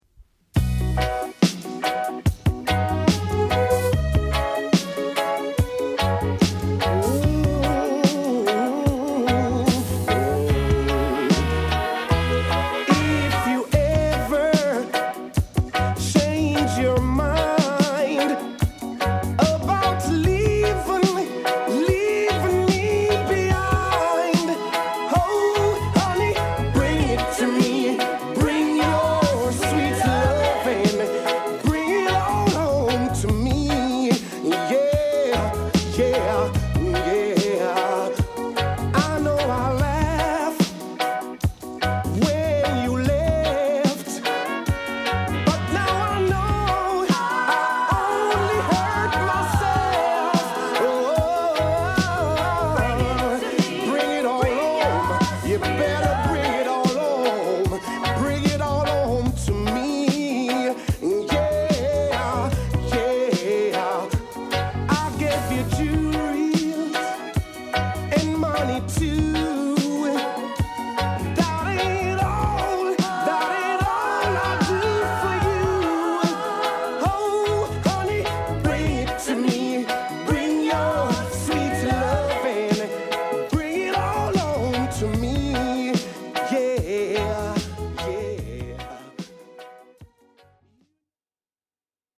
後半に重なるサックス・パートなど一曲通して素晴らしいです！